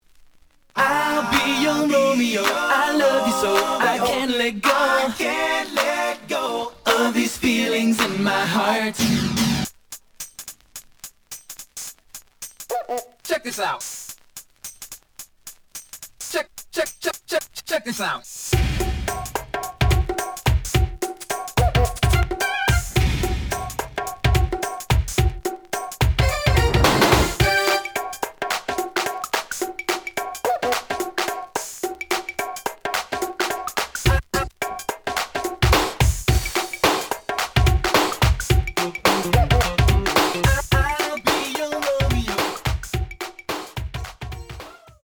試聴は実際のレコードから録音しています。
●Genre: Hip Hop / R&B